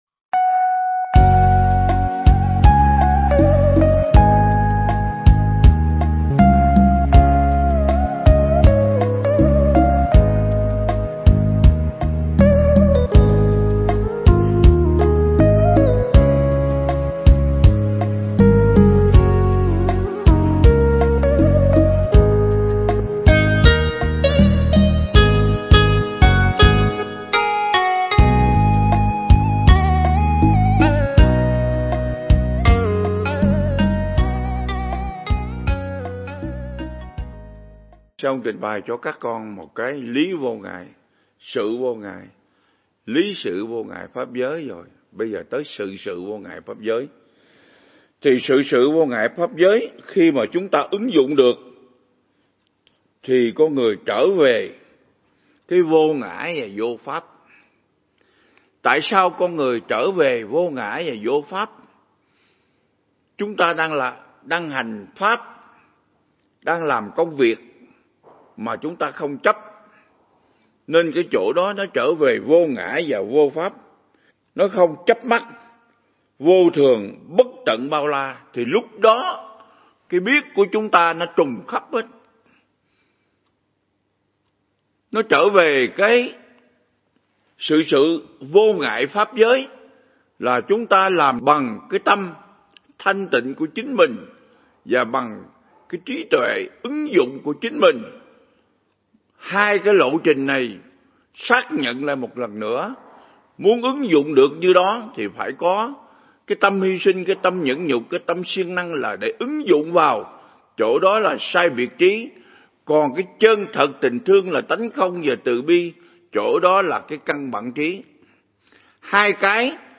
Mp3 Pháp Thoại Ứng Dụng Triết Lý Hoa Nghiêm Phần 19
giảng tại Viện Nghiên Cứu Và Ứng Dụng Buddha Yoga Việt Nam